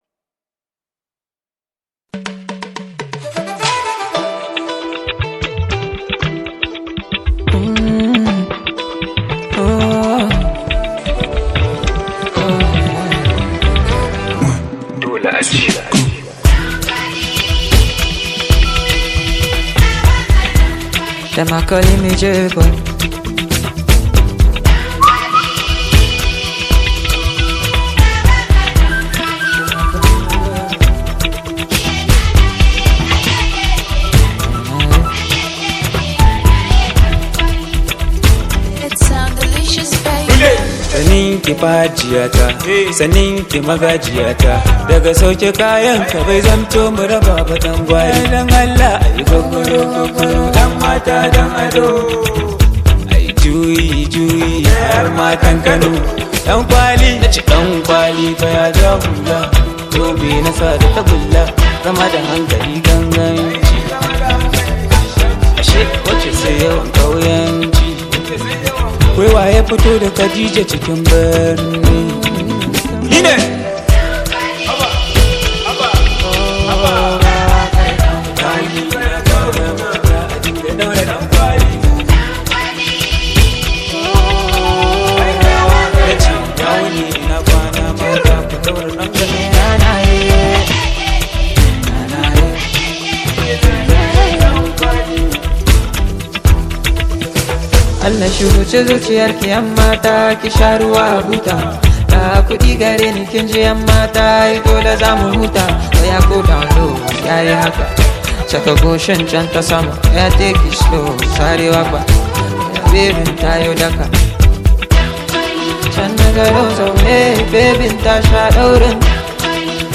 A fresh Arewa hit with soulful vibes.